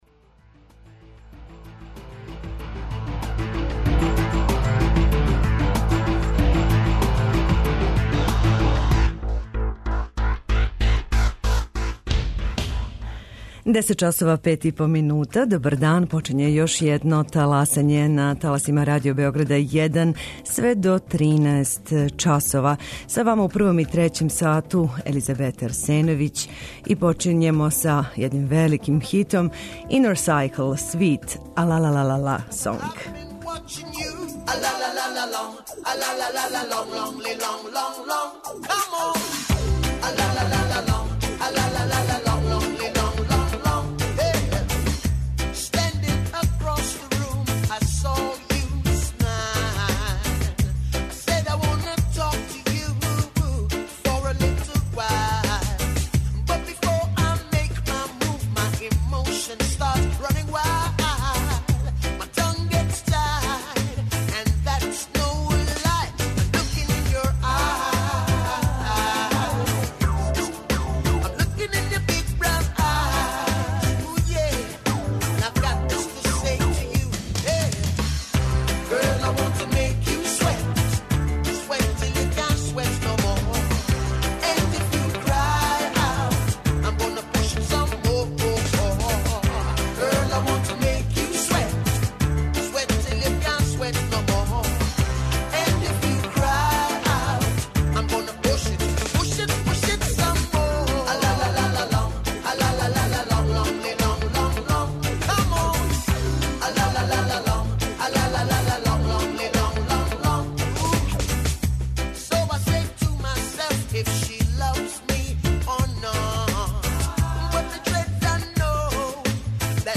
Начелница Општинске управе Бољевца, Мирослава Опачић, са двадесетогодишњим стажом на тој позицији, угостила нас је приликом наше недавне посете том градићу, који и изгледом, и положајем подно светски чувене планине Ртањ, са чијег врха се по ведром времену - кажу - може видети и двеста километара удаљени Београд, оставља изузетно пријатан утисак на сваког намерног или путника намерника.